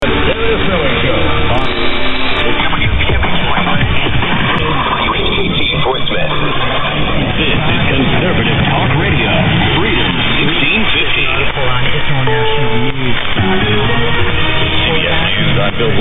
I had to listen twice to the following recording from 0300 22nd, as I initially thought I had heard the word FORT SMITH back on 1650.